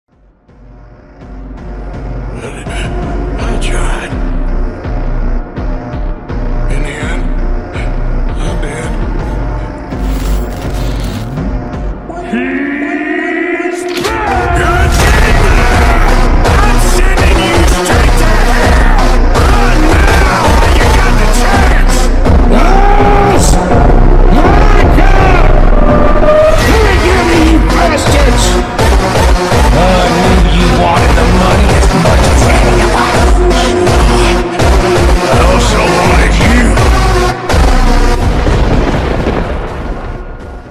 ultra slowed